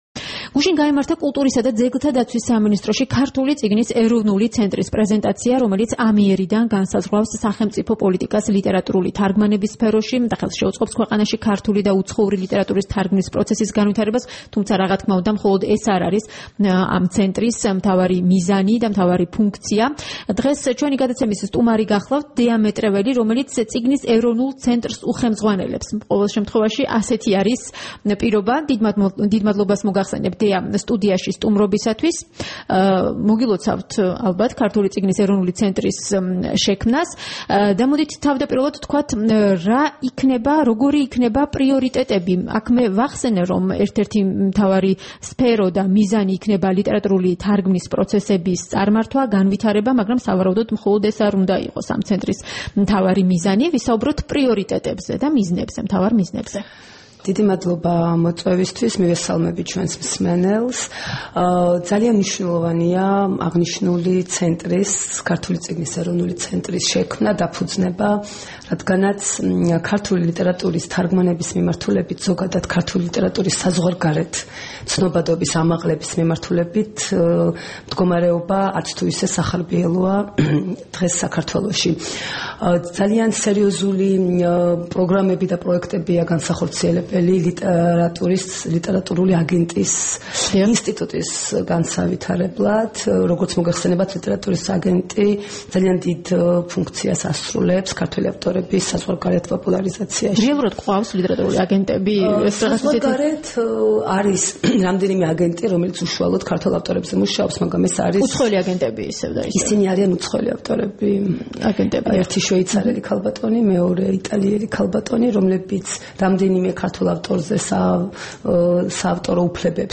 3 აპრილს რადიო თავისუფლების დილის გადაცემის სტუმარი იყო დეა მეტრეველი, წიგნის ეროვნული ცენტრის ხელმძღვანელი.
საუბარი დეა მეტრეველთან